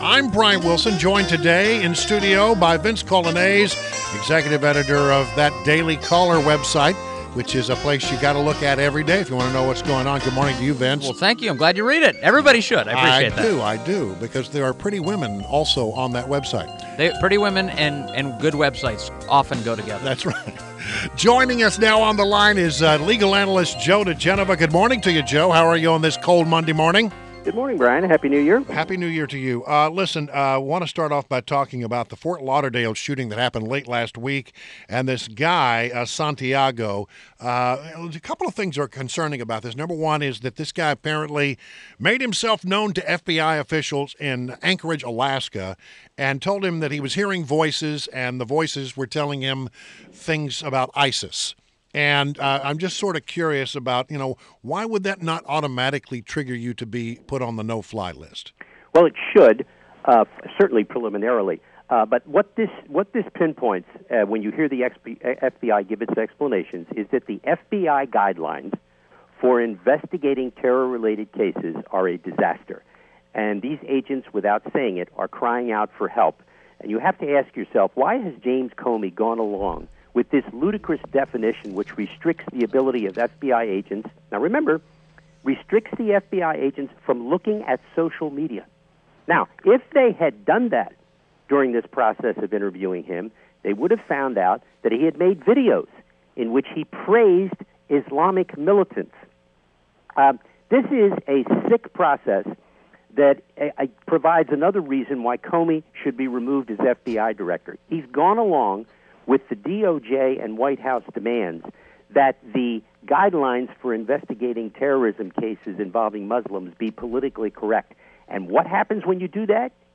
WMAL Interview - JOE DIGENOVA - 01.09.17
INTERVIEW — JOE DIGENOVA – legal analyst and former U.S. Attorney to the District of Columbia